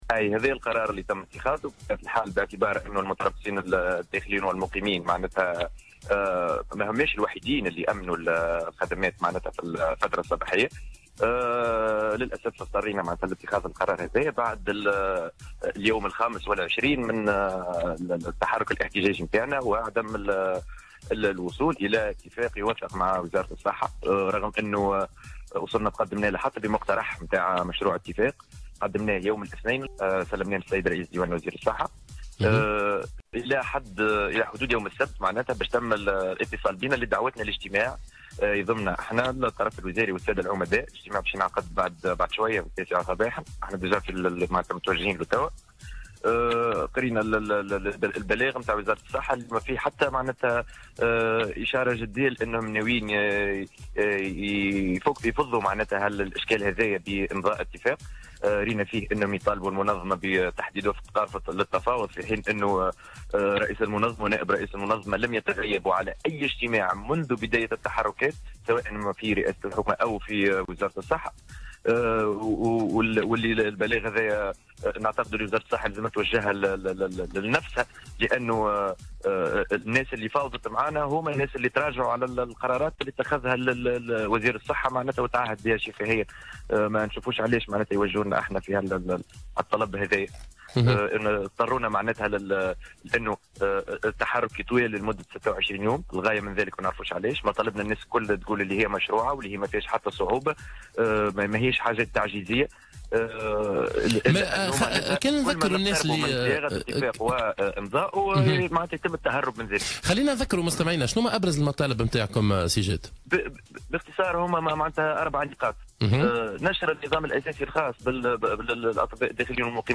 خلال مداخلة هاتفية له في برنامج "صباح الورد" اليوم الاثنين